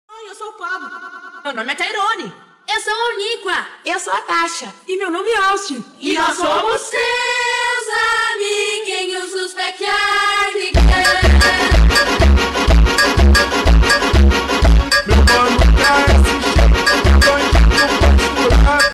phonk brazilian from Music
The “phonk brazilian” is a high-energy audio clip from Music commonly used in memes, TikToks, and YouTube shorts to create a comedic or chaotic vibe. Featuring a punchy beat with iconic "phonk", "brazilian", it's a staple in modern Music sound and meme culture.
phonk-brazilian